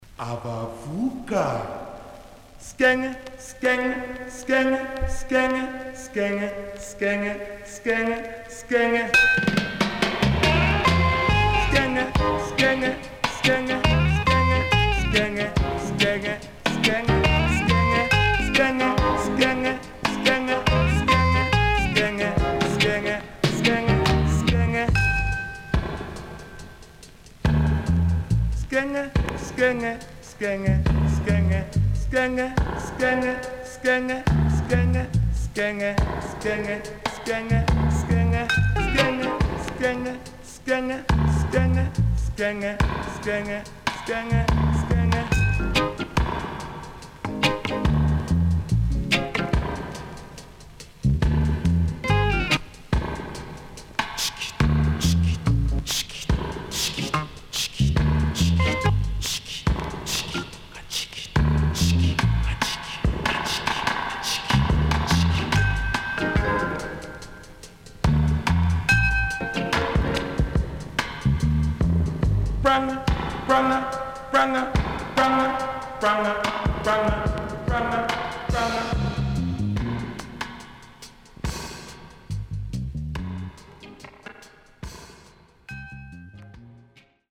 HOME > REGGAE / ROOTS
SIDE A:序盤小傷の為数発プチノイズ入ります。